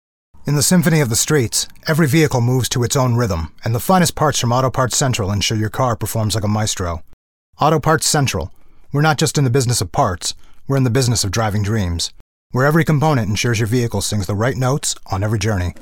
Male
Television Spots
Words that describe my voice are Warm, natural, enaging.